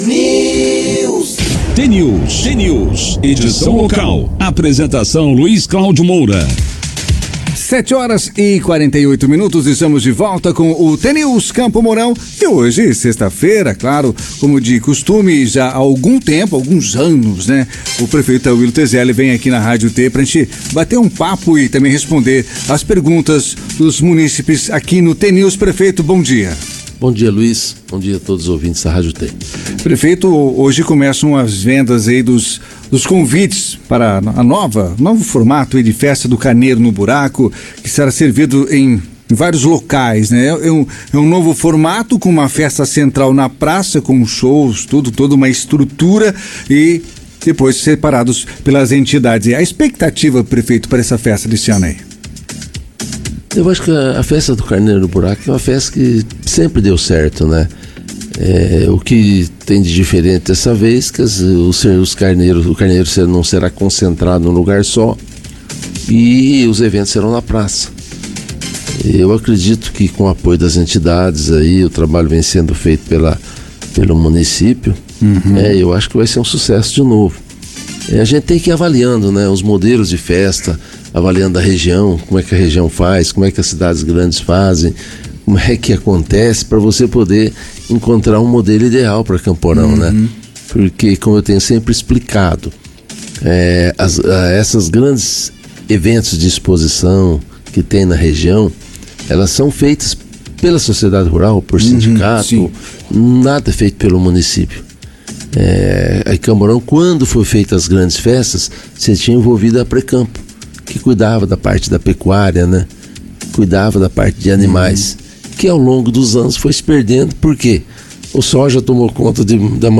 Como faz há mais de duas décadas, Tauillo Tezelli, atual prefeito de Campo Mourão, participou nesta sexta-feira, dia 9, do jornal T News, da Rádio T FM.